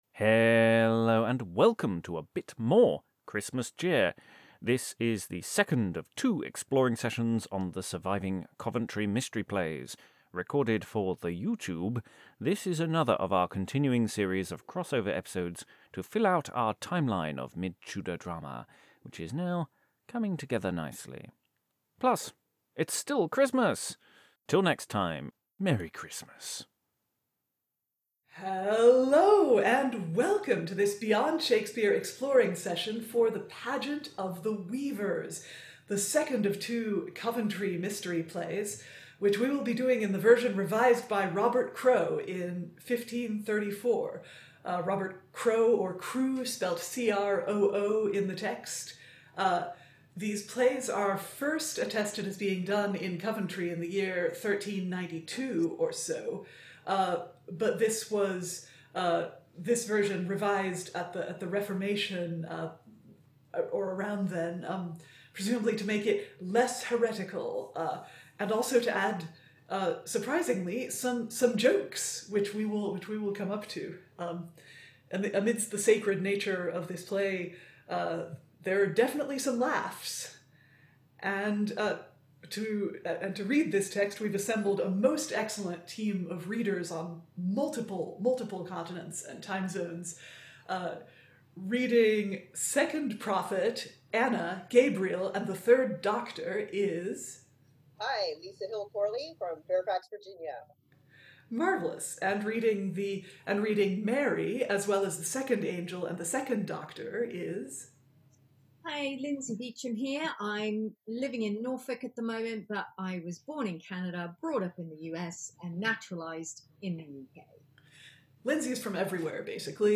A read through and discussion of the second of two surviving Coventry mystery plays, the text surviving from 1534.